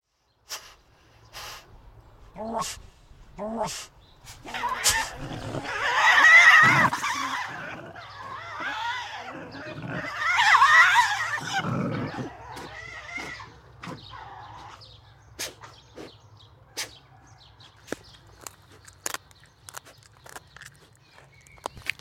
Звук визга тасманийского дьявола